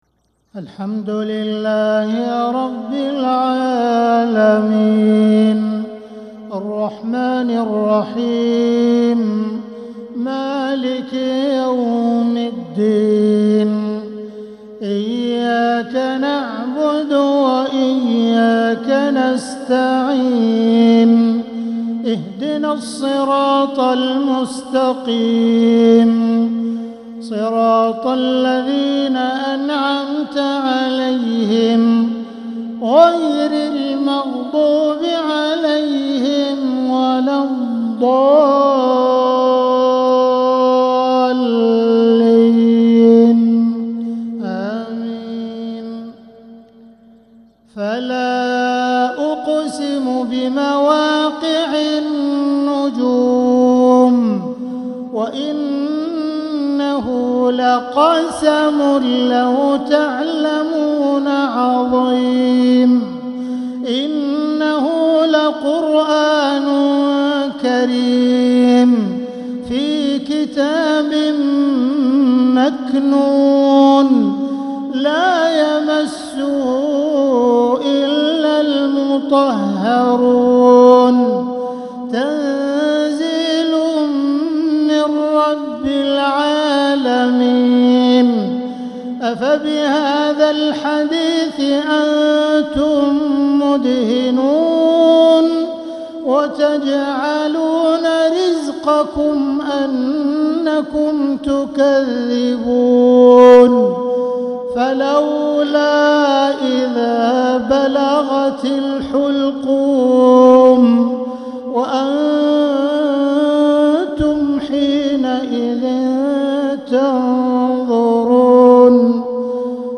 مغرب الأربعاء 14 محرم 1447هـ خواتيم سورة الواقعة 75-96 | Maghrib prayer from surah Al-Waqiah 9-7-2025 > 1447 🕋 > الفروض - تلاوات الحرمين